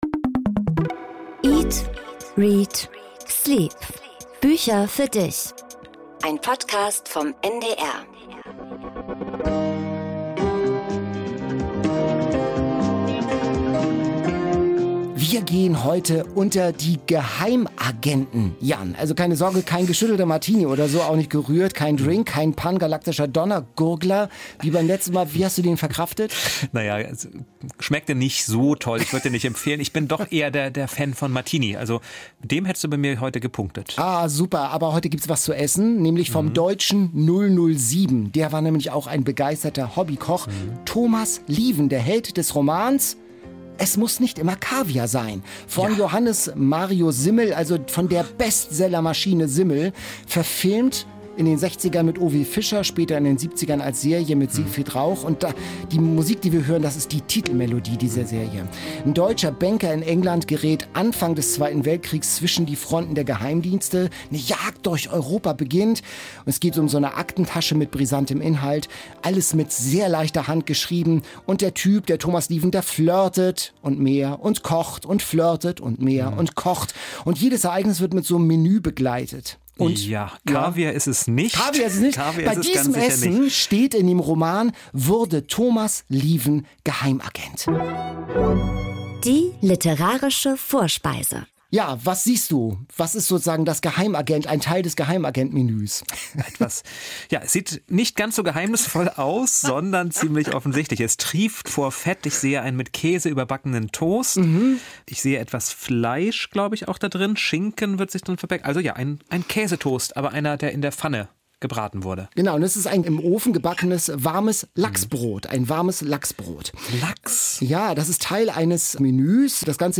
Essen für Geheimagenten, Bestseller im Test und T.C. Boyle im Interview - all das im NDR Bücher-Podcast eat.READ.sleep.
In der Bestsellerchallenge wird diesmal ohne Punkt und Komma heftig diskutiert. Und der Gast der Folge, T.C. Boyle, erzählt direkt aus seinem kalifornischen Wohnzimmer, wie man sich in den Kopf von Schimpansen hineinversetzt.